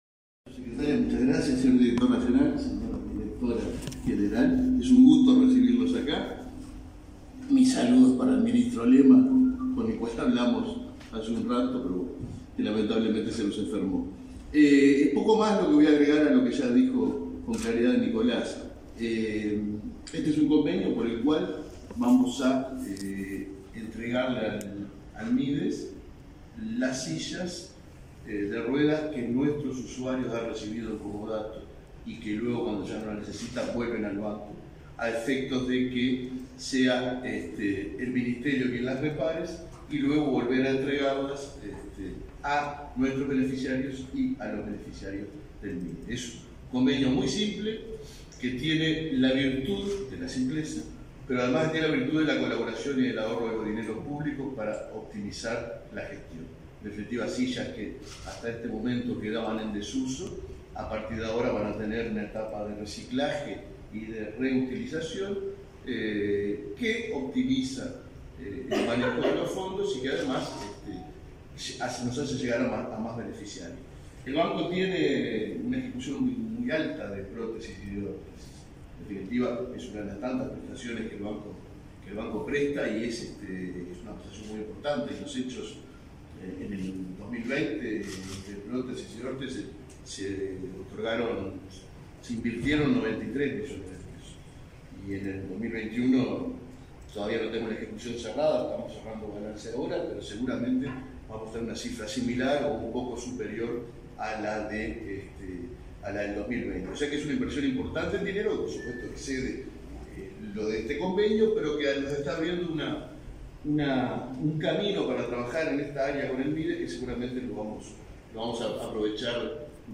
Conferencia de prensa por acuerdo entre el Mides y el BPS
El Ministerio de Desarrollo Social (Mides) firmó, este 21 de marzo, un acuerdo con el Banco de Previsión Social (BPS) mediante el cual beneficiarios de programas de la cartera accederán a sillas de ruedas reacondicionadas que permanecían inutilizadas. Participaron el presidente del BPS, Alfredo Cabrera, y el secretario de Cuidados, Nicolás Scarela.